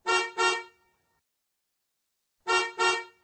cf_car_horn.ogg